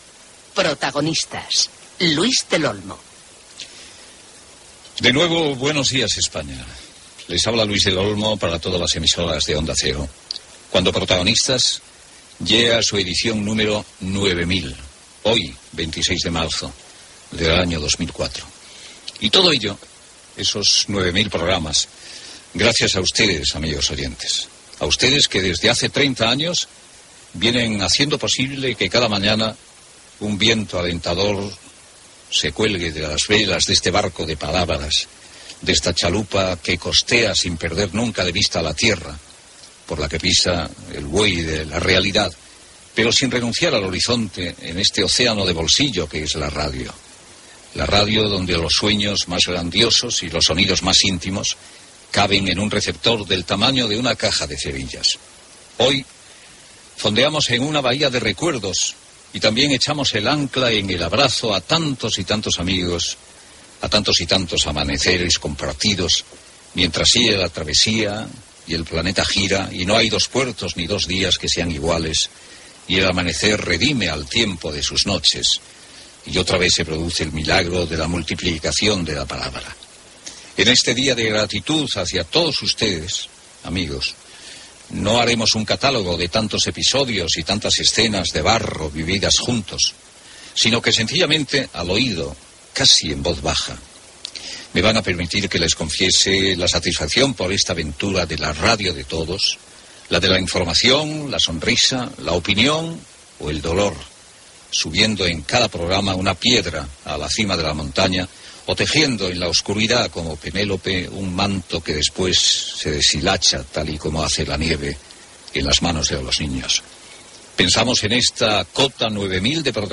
Indicatiu del programa, comentari sobre el fet d'haver complert 9000 programes, Sintonia històrica, presentació dels col·laboradors, indicatiu, publicitat
Gènere radiofònic Info-entreteniment